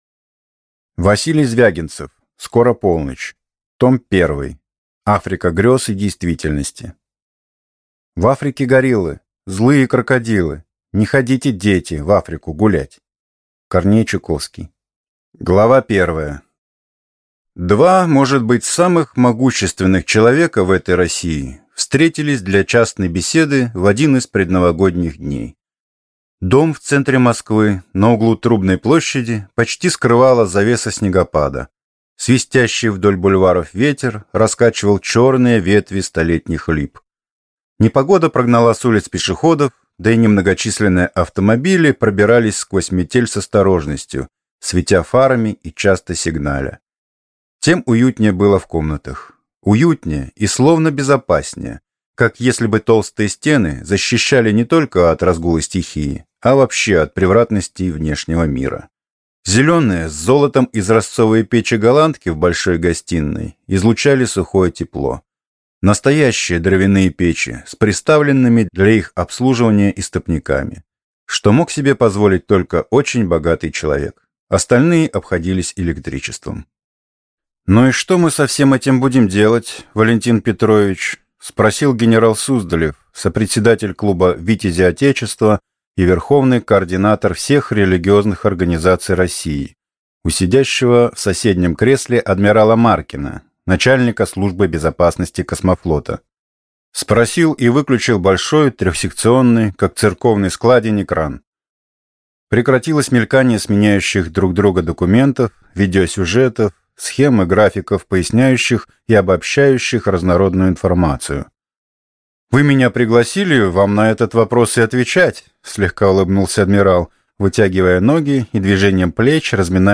Аудиокнига Скоро полночь. Том 1. Африка грёз и действительности | Библиотека аудиокниг